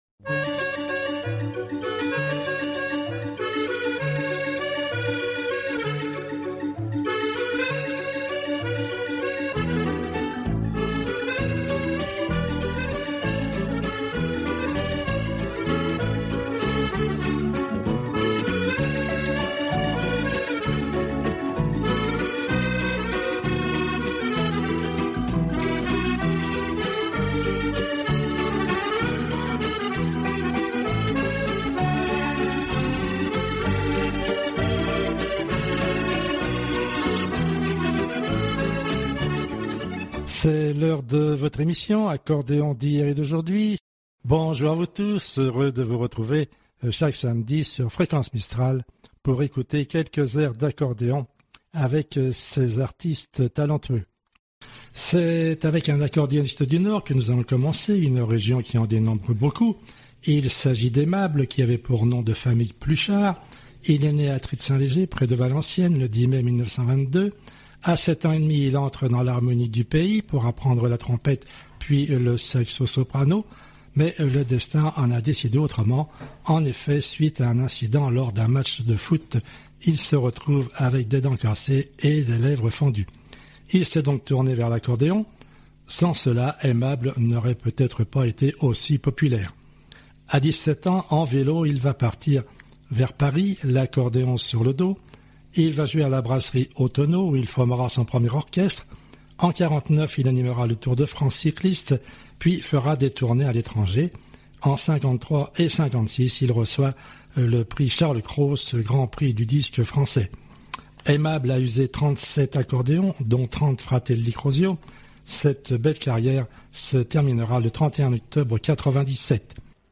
pour les amoureux d'Accordéon